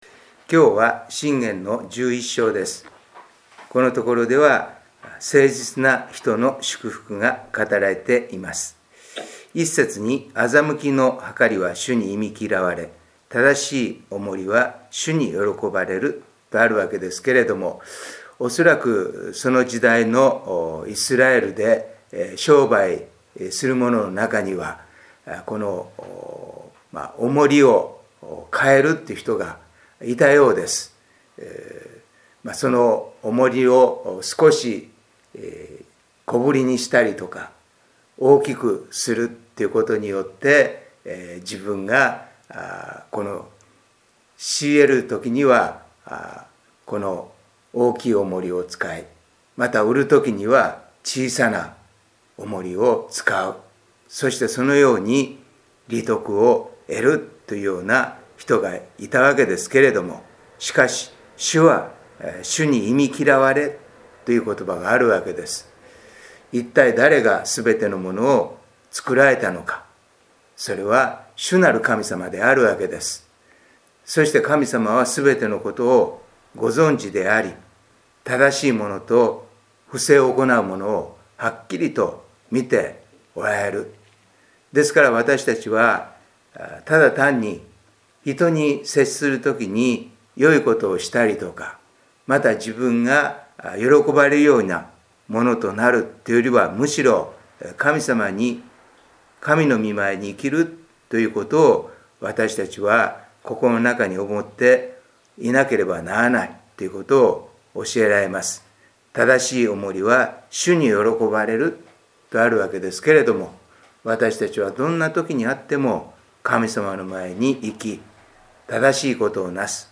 2022/3/9 聖書研究祈祷会